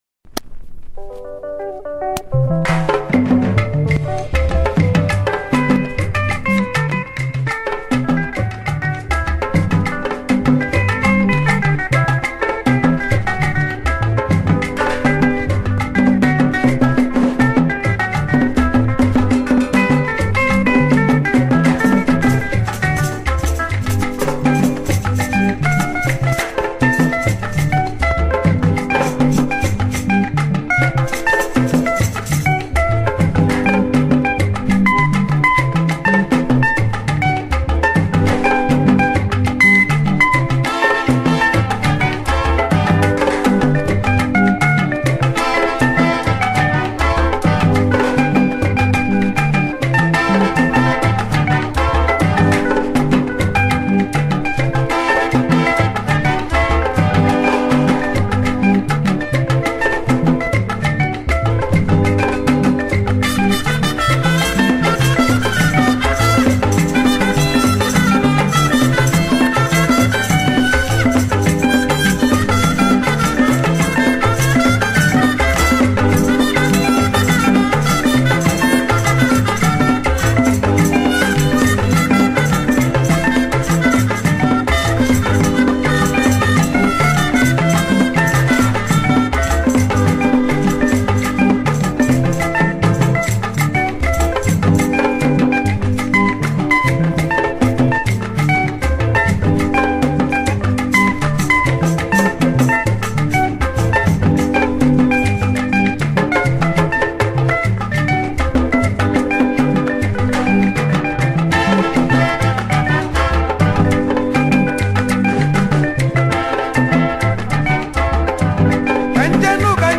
September 10, 2024 admin Highlife Music, Music 0